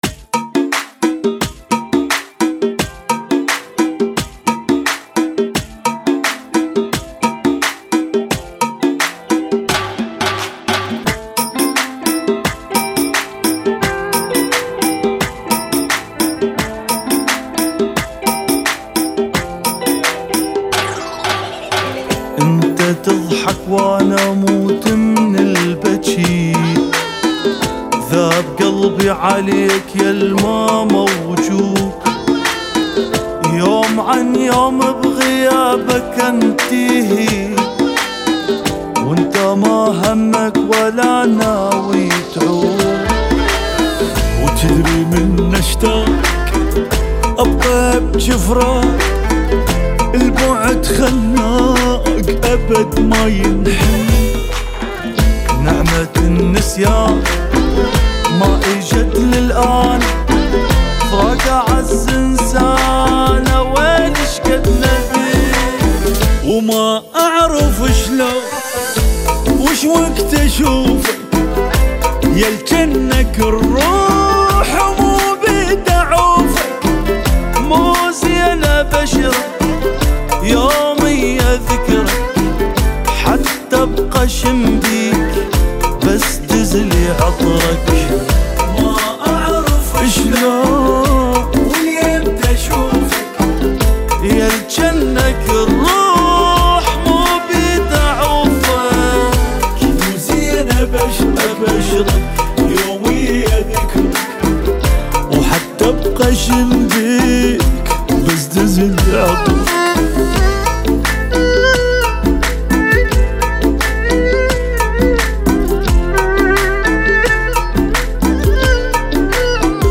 [ 87 bpm ]